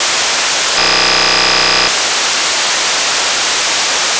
Сигнал111